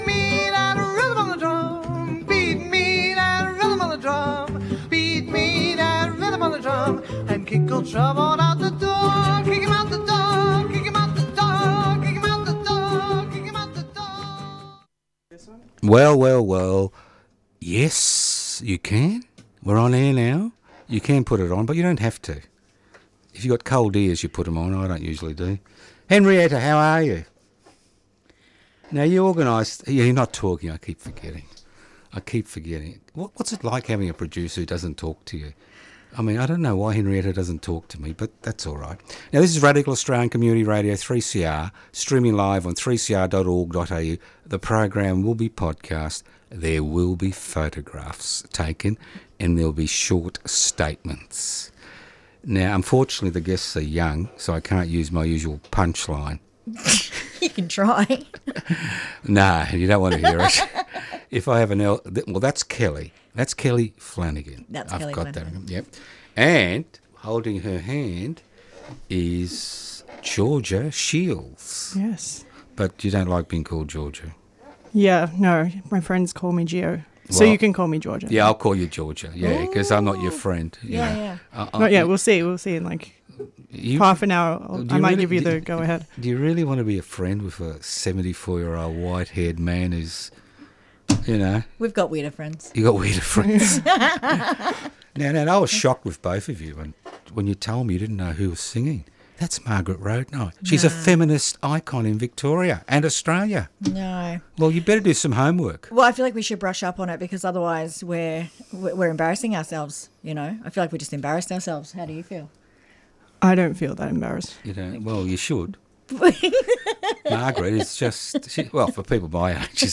***Content & language warning***